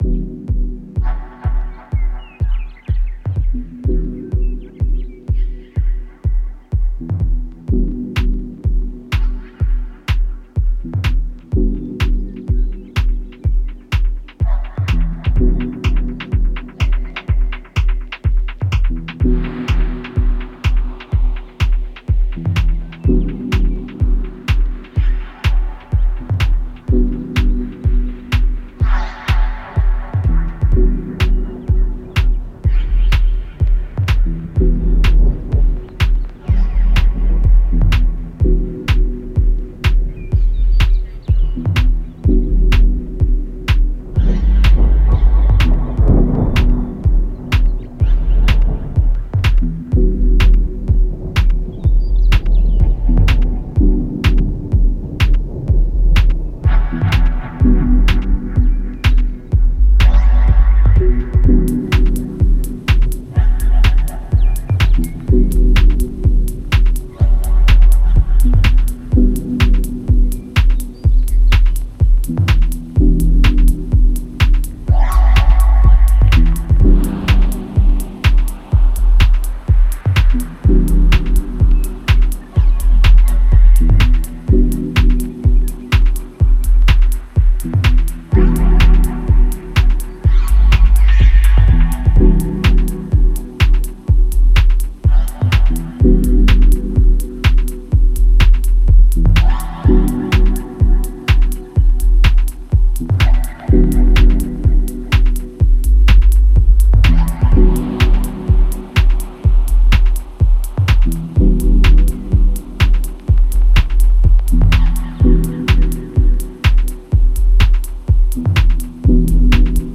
Genre: Dub Techno/Ambient/Deep House.